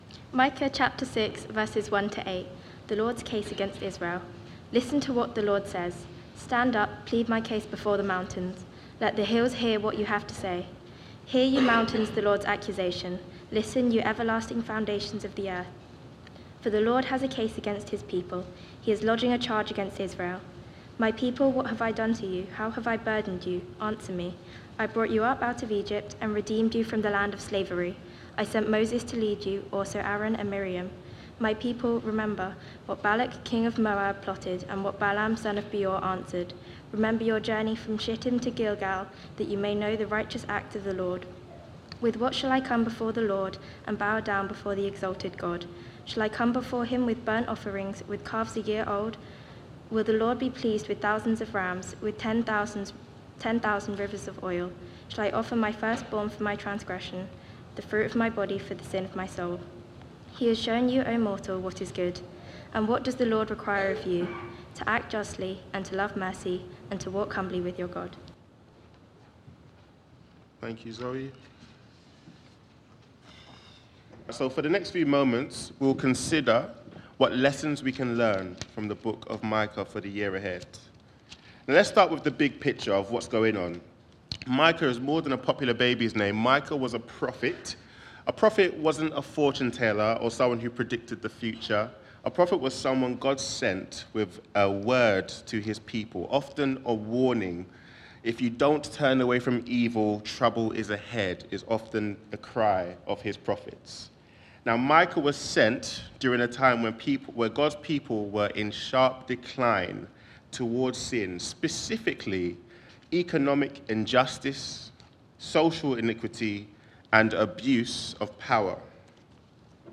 Media for Sunday Service on Sun 29th Dec 2024 10:00
Sermon